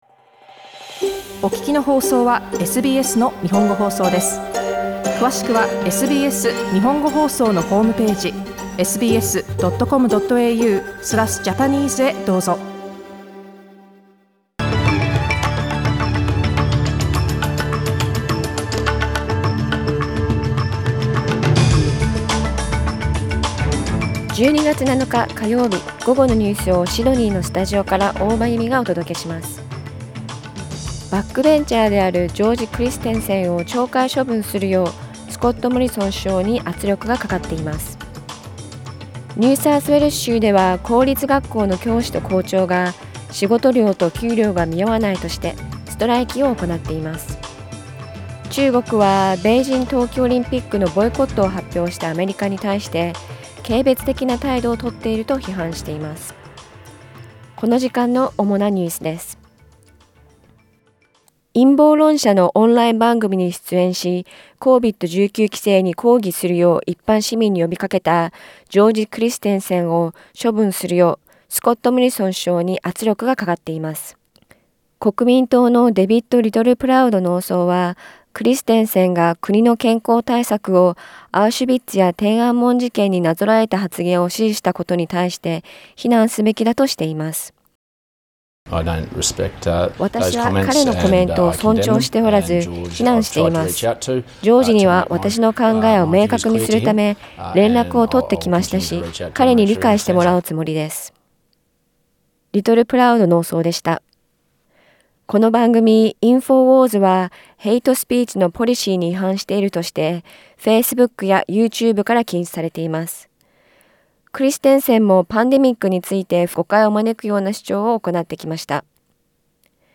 12月7日 午後のニュース
Afternoon news in Japanese, 7 December 2021